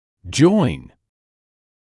[ʤɔɪn][джойн]соединять; соединяться; присоединяться